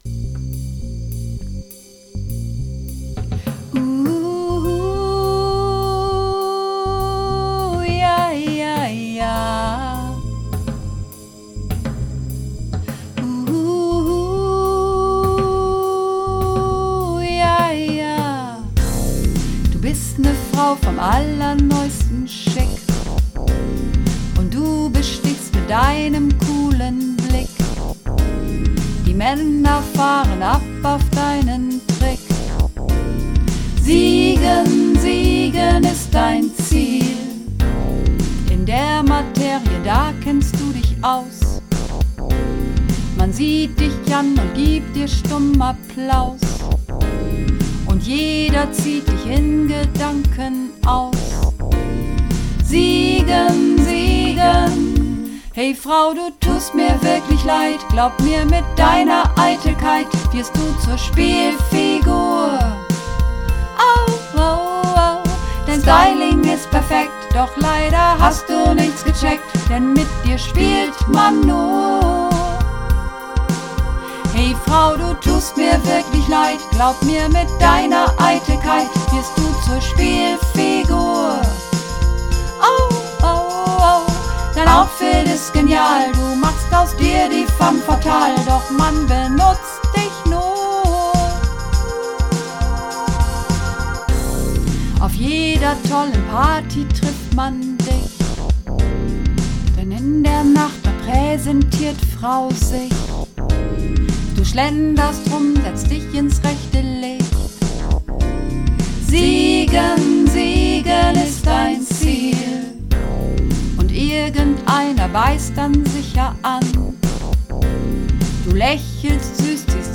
(Mehrstimmig)